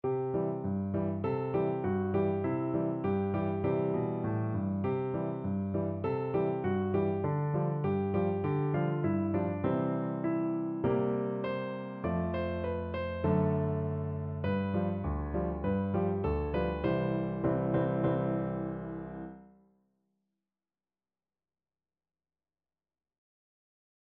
Free Sheet music for Piano Four Hands (Piano Duet)
4/4 (View more 4/4 Music)
Piano Duet  (View more Beginners Piano Duet Music)